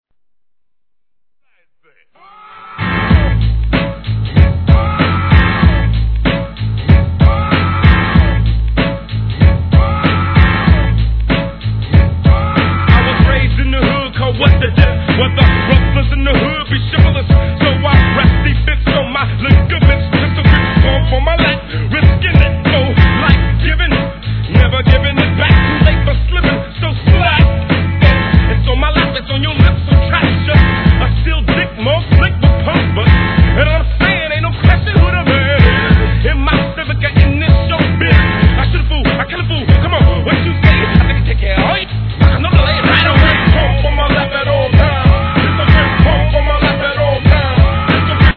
G-RAP/WEST COAST/SOUTH
様々なGANGSTA RAPで使用された定番FUNKネタでのWEST COAST CLASSIC!!